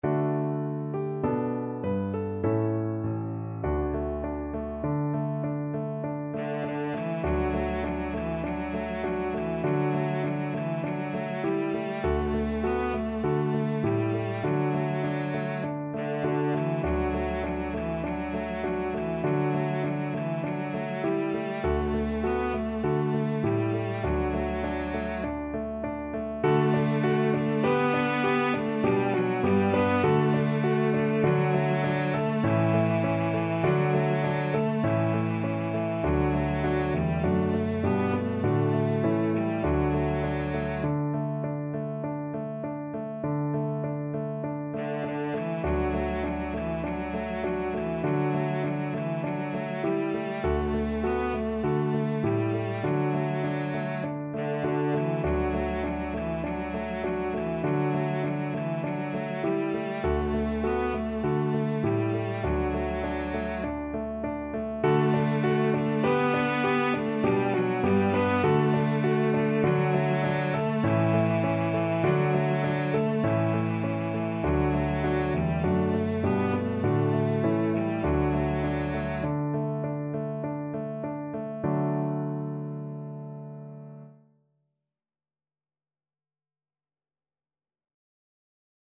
Cello
D major (Sounding Pitch) (View more D major Music for Cello )
Moderato
4/4 (View more 4/4 Music)
Traditional (View more Traditional Cello Music)